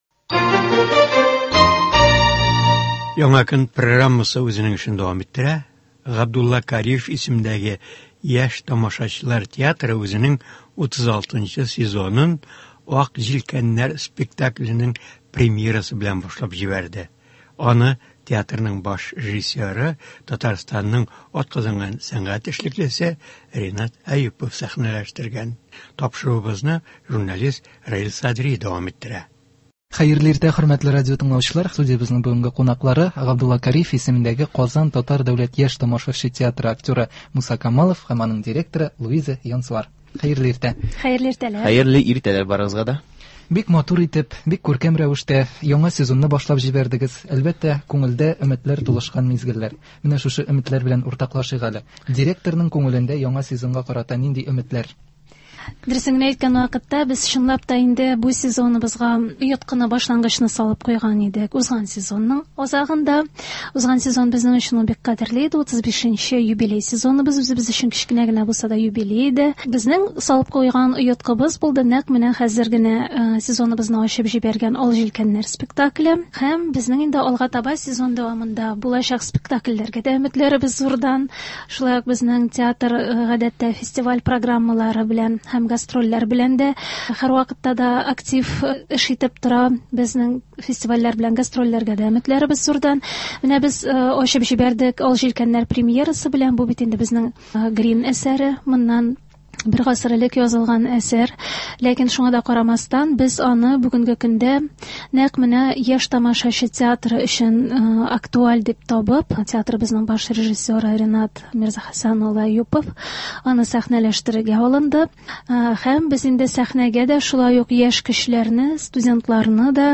Студиябездә кунакта